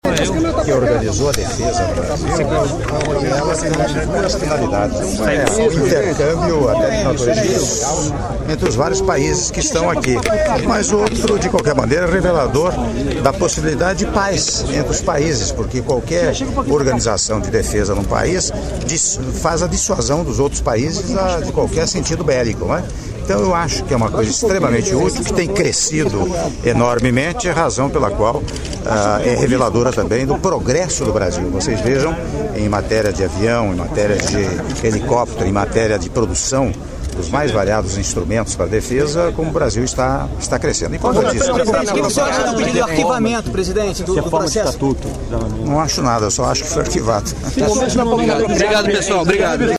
Entrevista coletiva concedida pelo Presidente da República em exercício, Michel Temer, após cerimônia de abertura da 8ª edição da LAAD Defence e Security - Rio de Janeiro/RJ